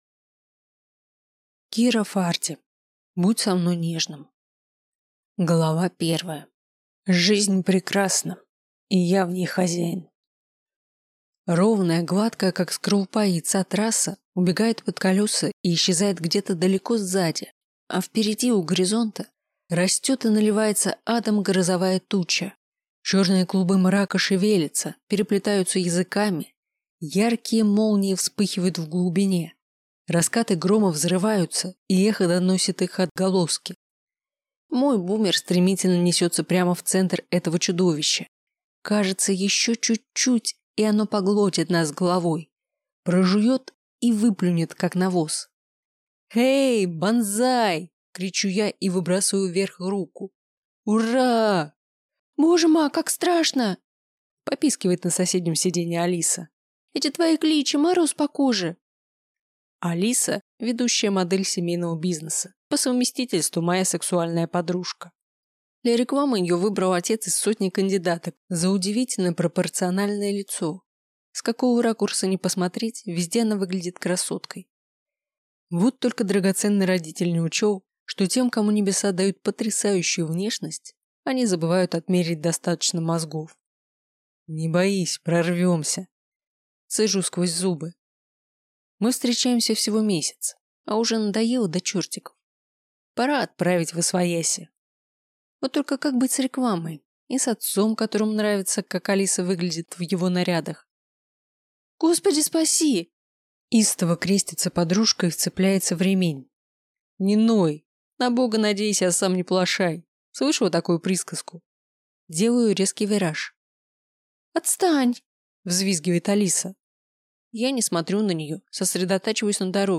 Аудиокнига Будь со мной нежным | Библиотека аудиокниг